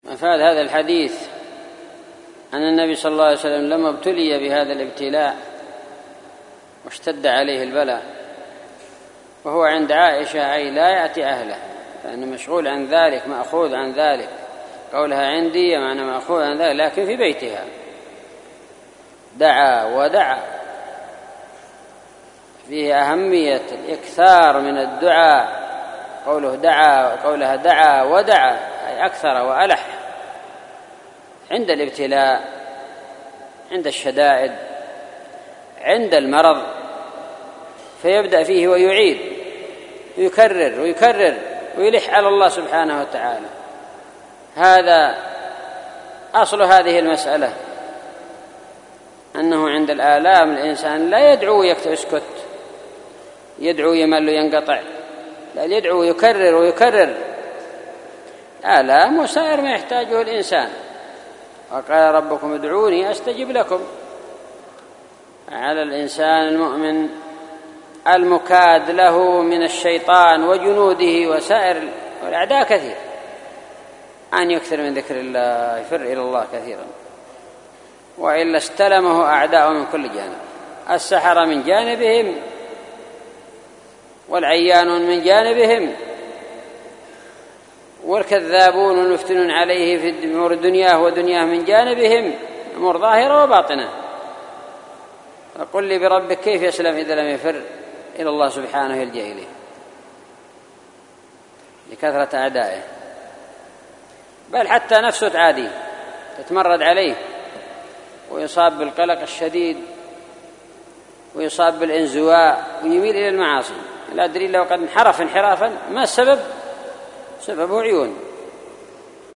شرح حديث رقم ( 5763 )